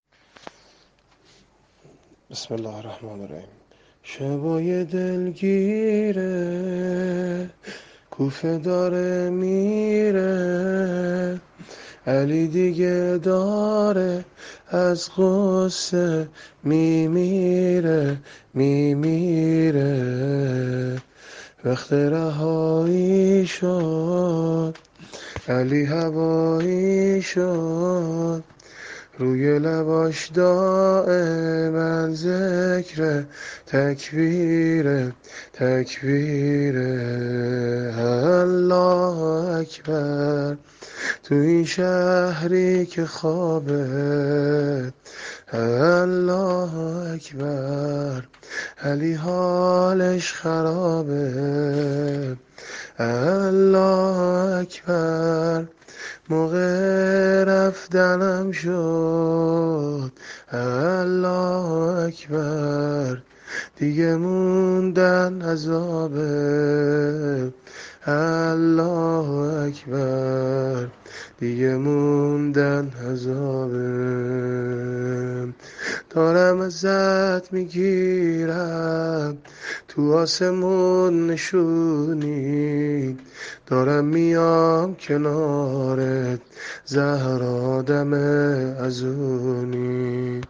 زمینه شب ۱۹ رمضان -(شبای دلگیرِ، کوفه داره میره)